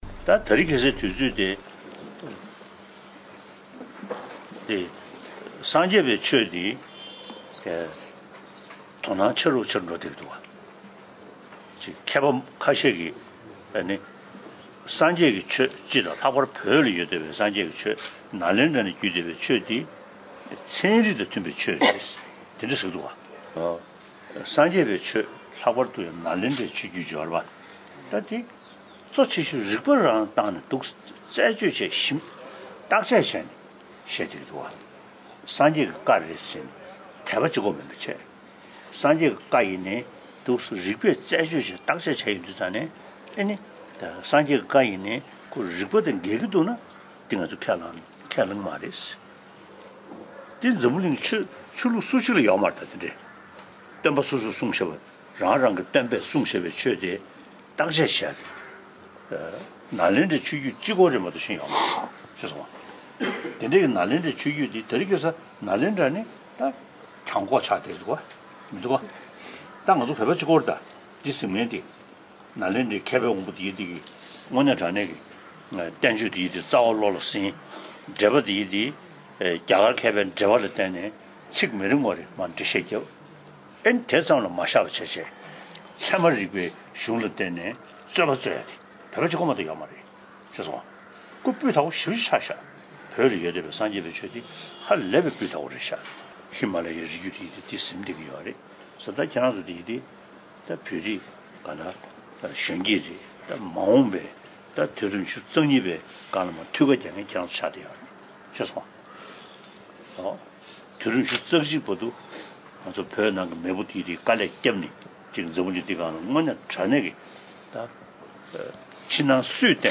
༧གོང་ས་མཆོག་གིས་བསྩལ་བའི་བཀའ་སློབ་འདིར་གསན་རོགས་ཞུ།